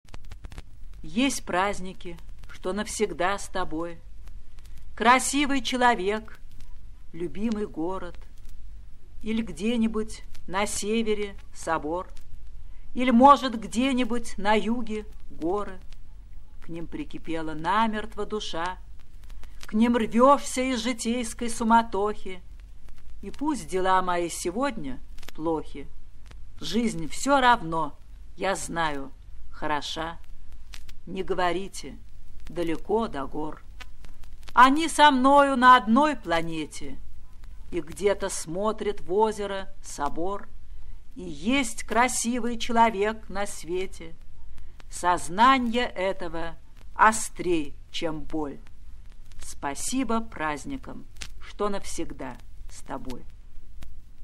1. «Юлия Друнина – Есть праздники, что навсегда с тобой… (читает автор)» /
yuliya-drunina-est-prazdniki-chto-navsegda-s-toboj-chitaet-avtor